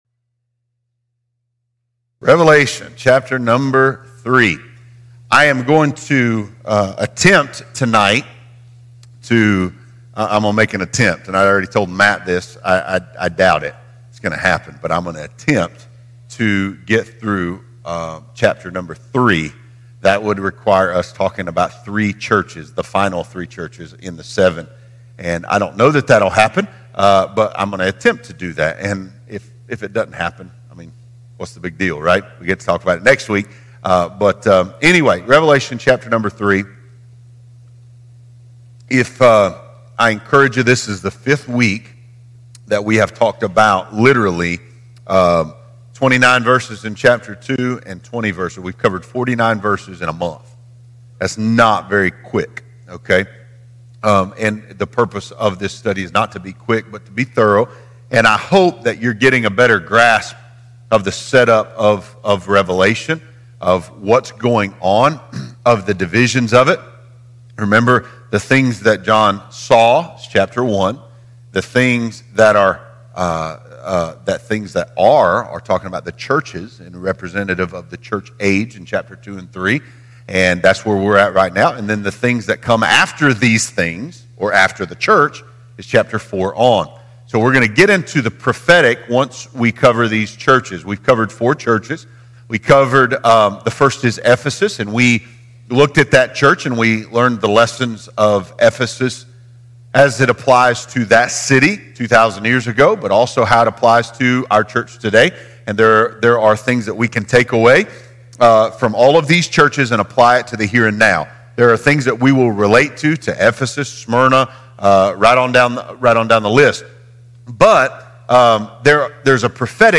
Have you ever wondered what the book of Revelation really means and how it relates to the current times? This is a verse-by-verse, bite-by-bite, in-depth Bible study that does not speculate or guess; letting Scripture speak and authenticate this important, but often overlooked book in the Bible.